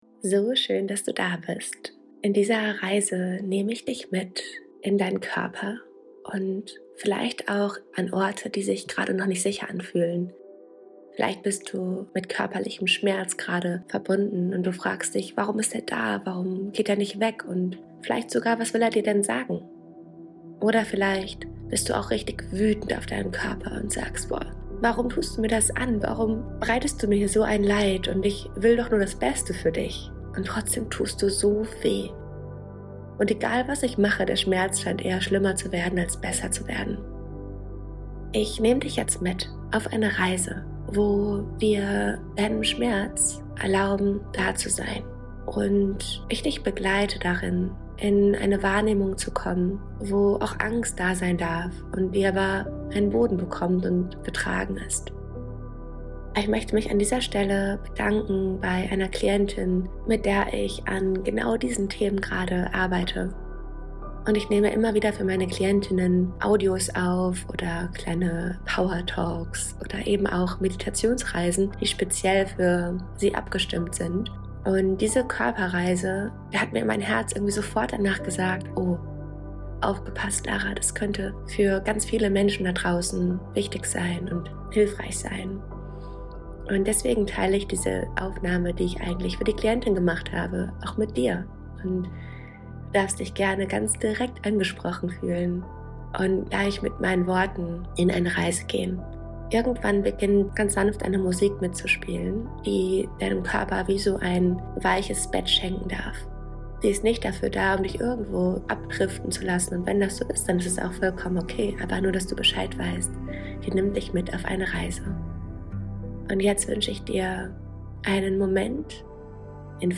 Diese geführte Meditation ist eine Einladung, deinem Körper auf eine neue Weise zu begegnen. Wenn du körperliche Schmerzen spürst, ob akut, wiederkehrend oder chronisch, begleite ich dich in dieser sanften Praxis zurück in die Verbindung mit dir selbst.